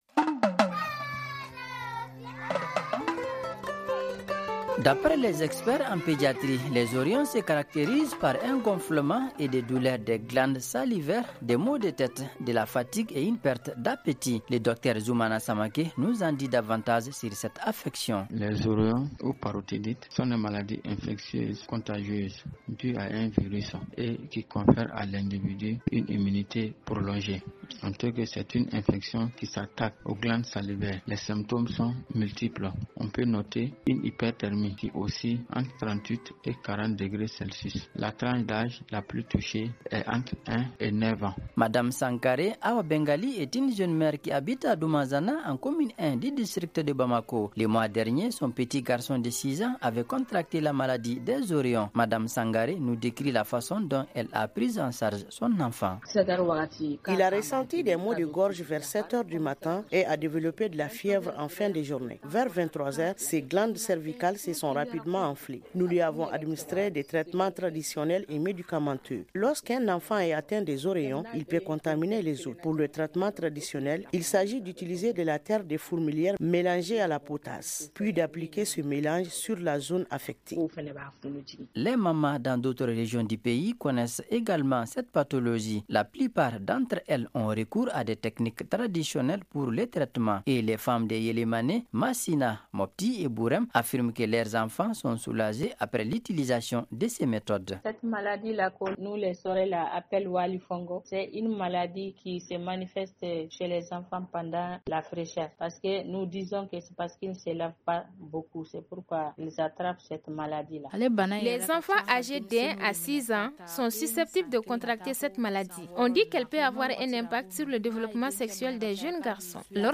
Ci-dessous écoutez le magazine en français: